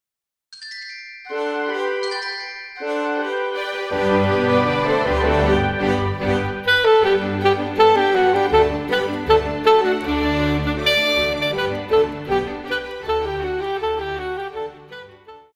Classical
Saxophone-Alto
Orchestra
Instrumental
Only backing